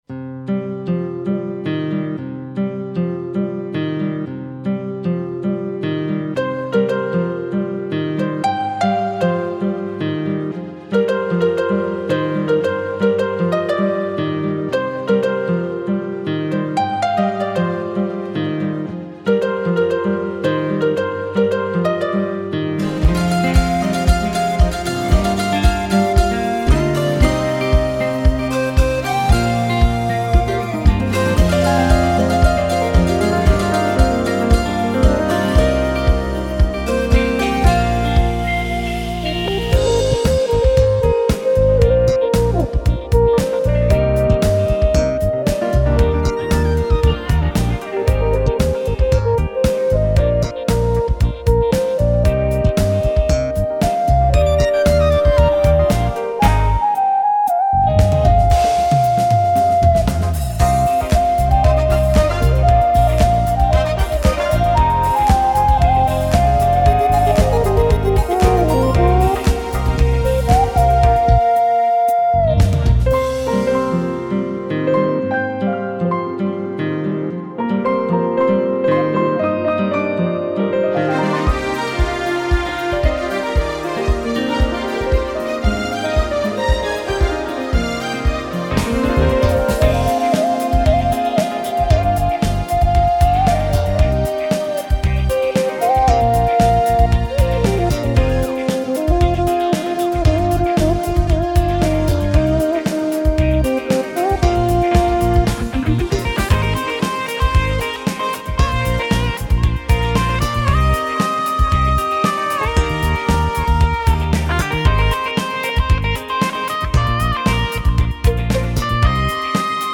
בעיבוד קייצי מיוחד
קלידים